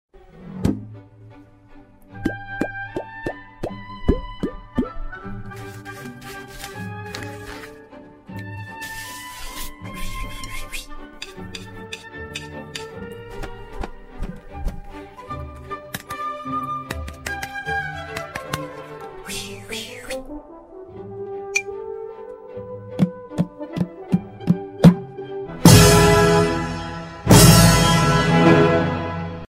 Sound Effects (On Drums!)